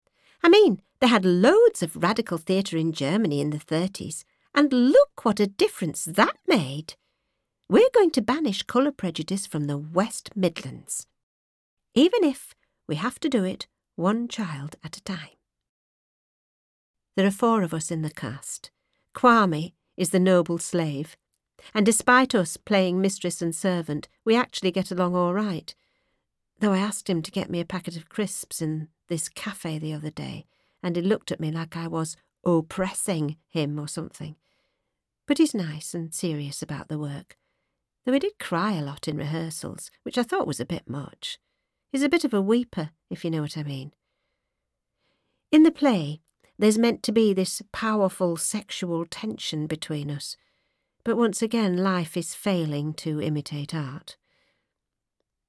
【有声英语文学名著】CHAPTER TWO（2） 听力文件下载—在线英语听力室